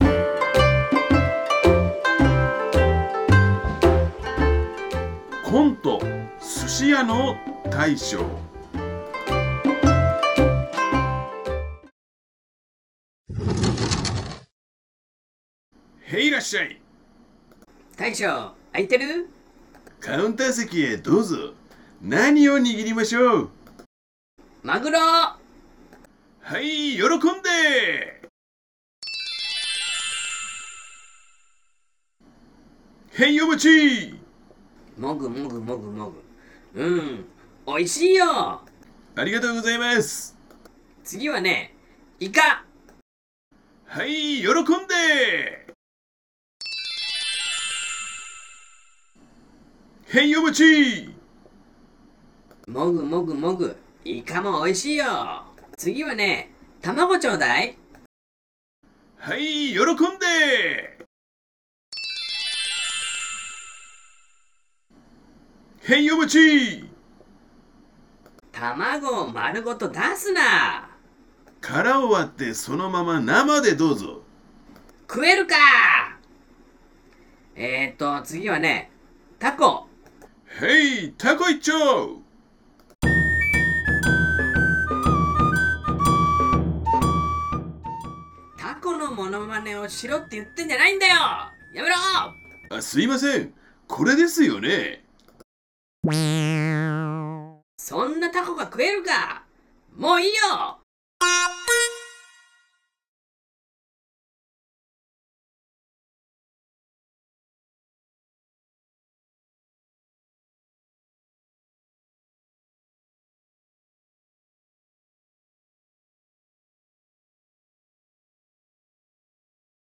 03_コント_寿司屋の大将-1.m4a